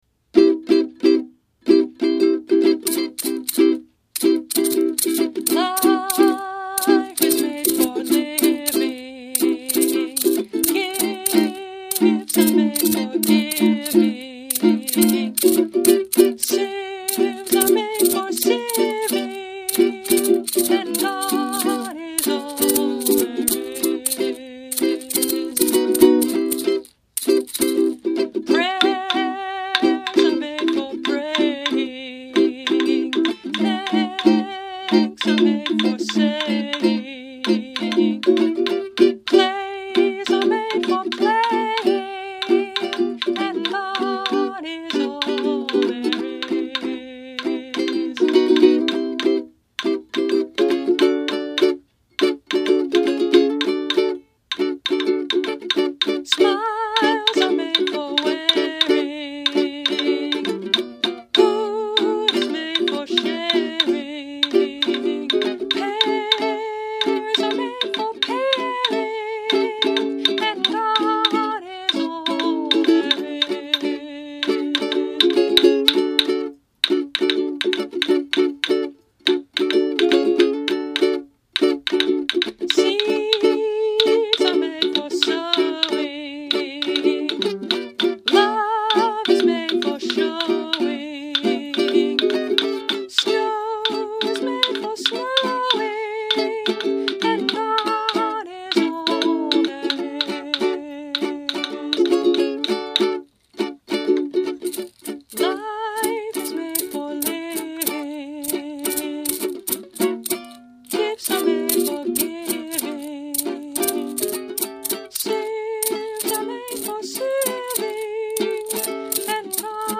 A fun Samba, great for children!
Instruments: Claves, Maraca, Eventide – Mainland Mahogany Concert Ukulele